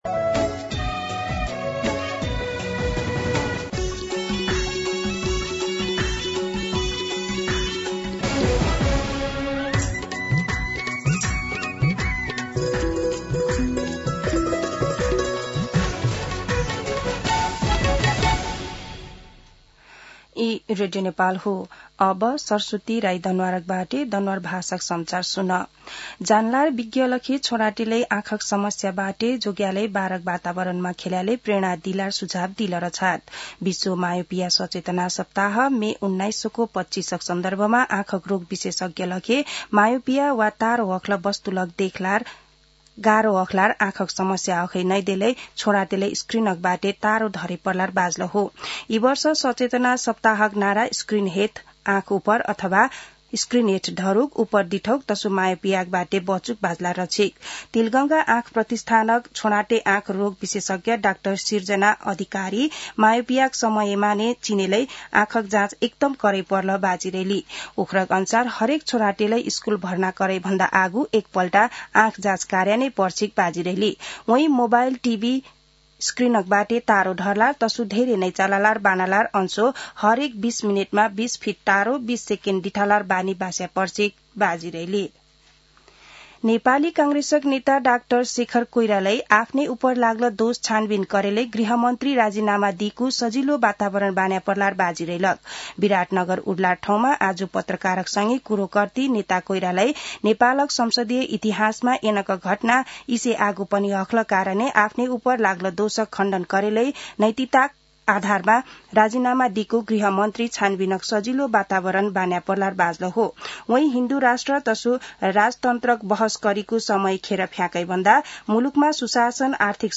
दनुवार भाषामा समाचार : १० जेठ , २०८२